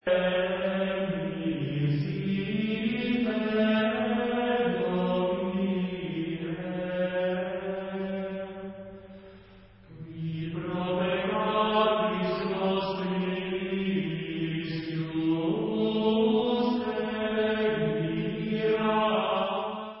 Antiphona